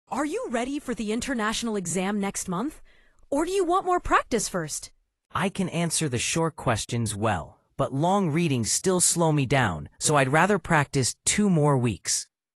Dialogue: